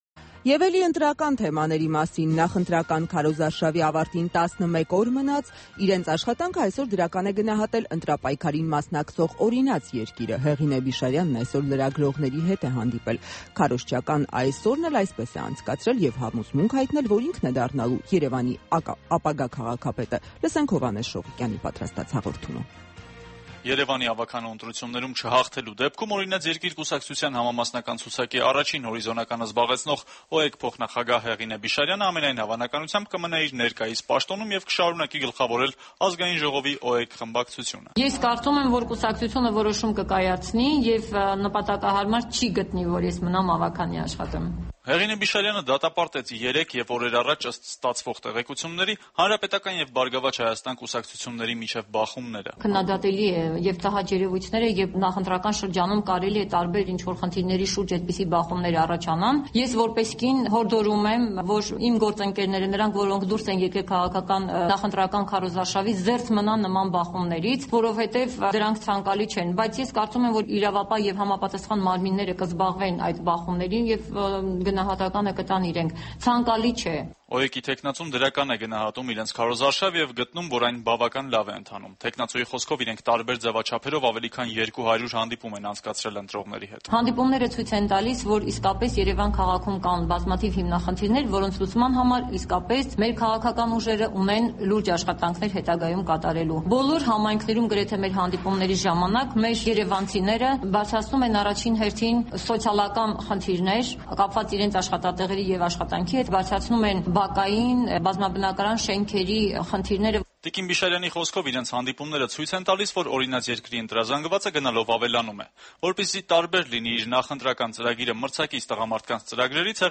Հեղինե Բիշարյանի ասուլիսը